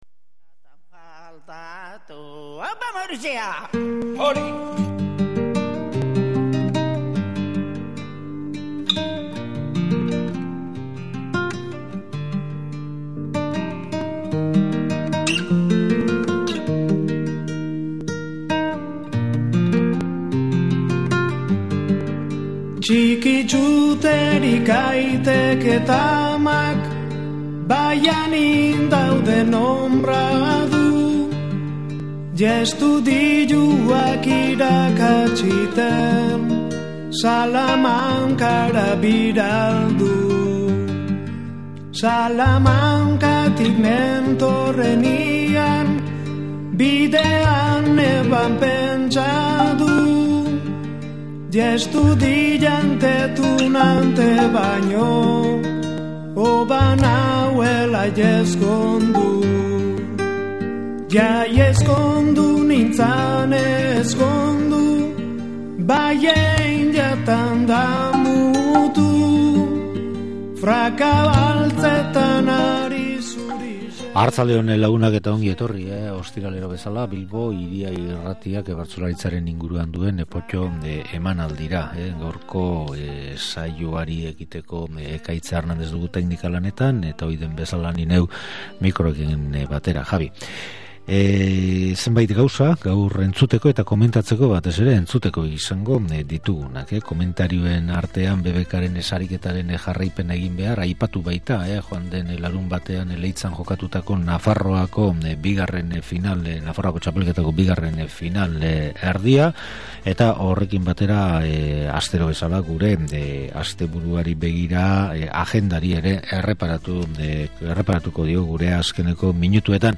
Bizkaian ibili zaigu aste honetan Potto irratsaioa. Lehenengo, Mungia aldera jo du, San Antontxu eguneko saioan botatako bertsoetatik aukeraketa adierazgarri bat egiteko.
Handik Portugaletera joan da, Ezkerraldean euskara eta euskal kultura sustatzen diharduen Harribola elkarteak antolatutako bertso saioa eskaintzeko.